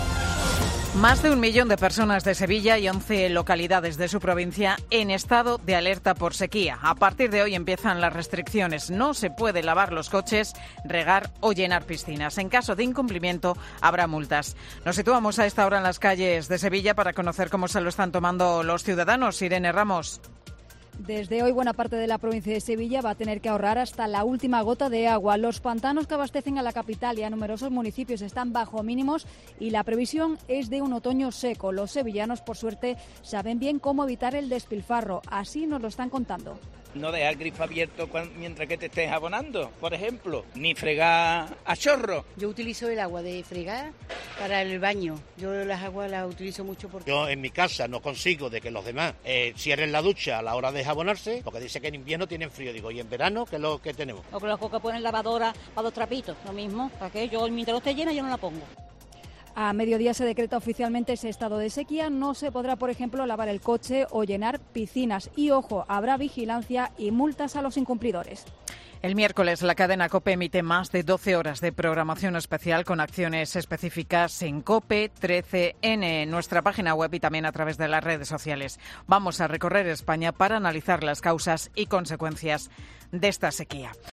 ¿Qué opinan los sevillanos de las prohibiciones para ahorrar agua por la sequía? Crónica